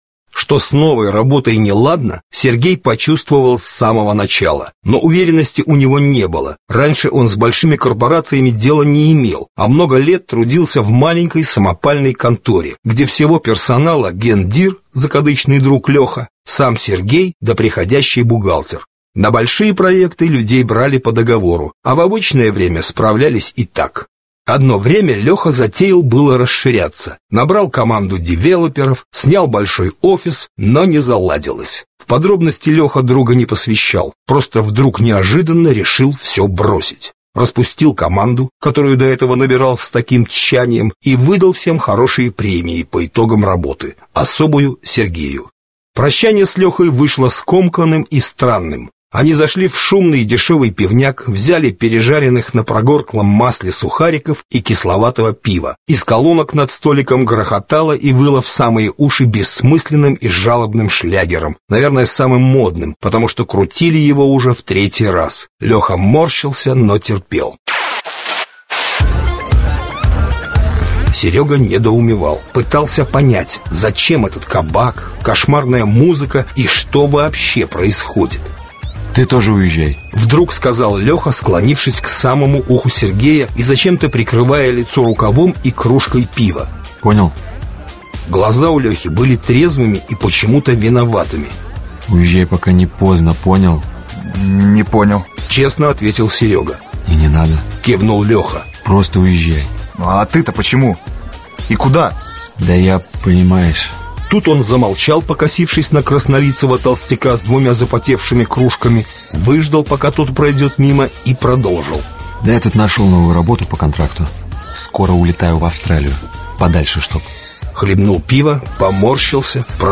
Аудиокнига Вирус | Библиотека аудиокниг
Aудиокнига Вирус Автор Татьяна Томах Читает аудиокнигу Арт-группа NEOСФЕРА.